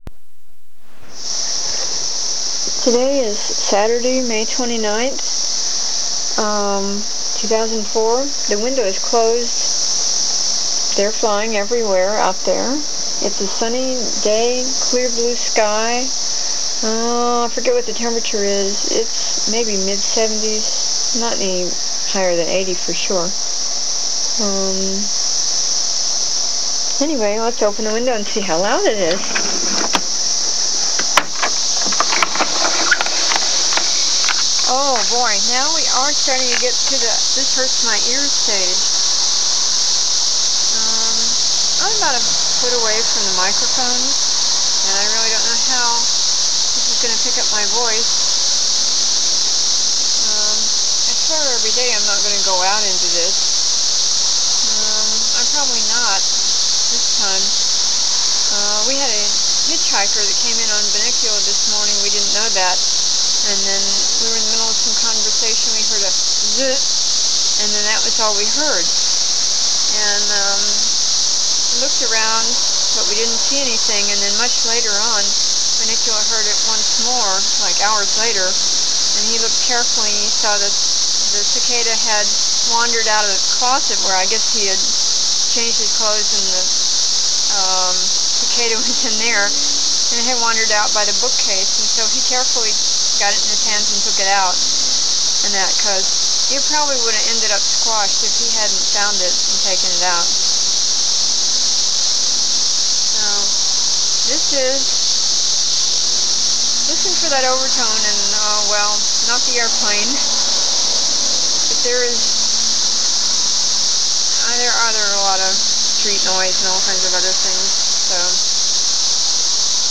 Warning: This is cicada sound, but with a LOT of commentary.
Saturday, May 29, 2004, recorded from indoors at treetop level (getting to
hurting-ears level now), and in which I recount how a cicada comes out of the